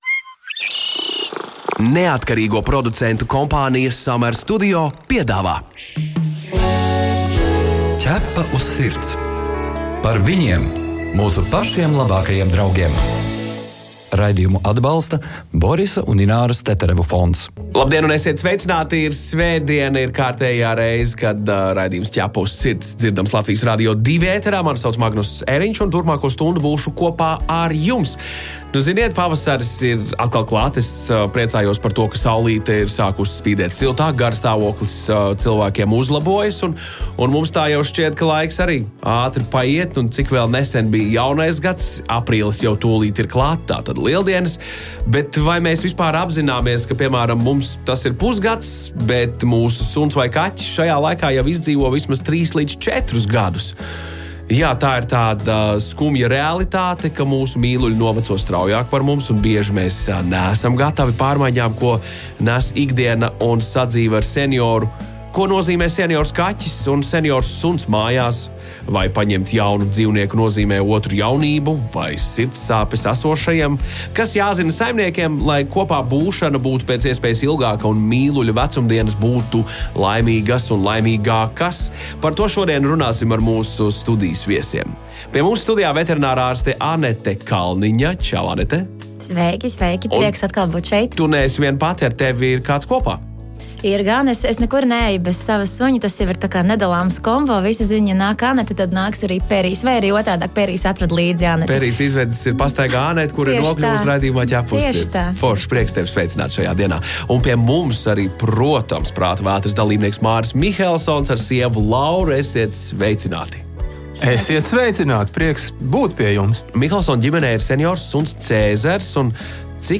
Studijas viesi